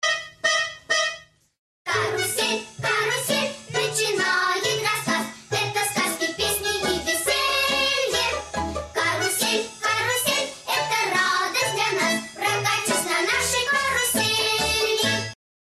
• Качество: 128, Stereo
заставка